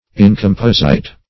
Search Result for " incomposite" : The Collaborative International Dictionary of English v.0.48: Incomposite \In`com*pos"ite\, a. [L. incompositus.